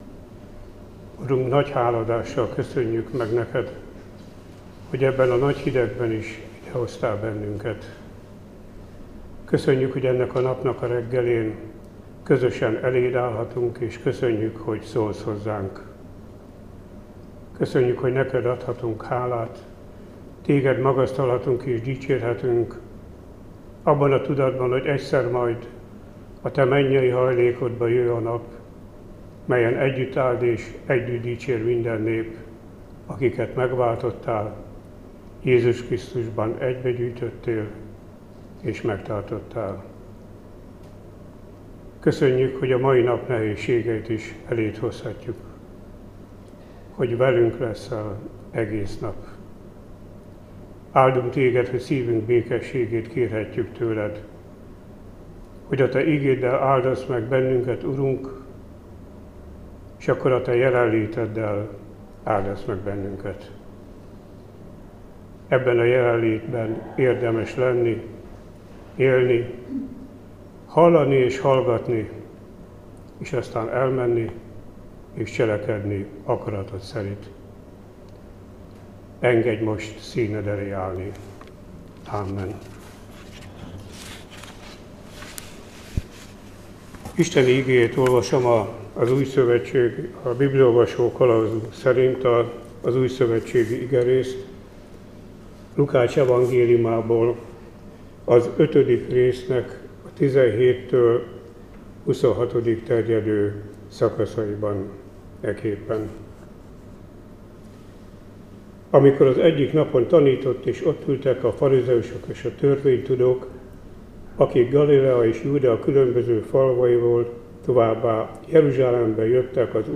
Áhítat, 2025. január 14.